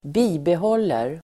Uttal: [²b'i:behål:er]